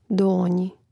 dȏnjī donji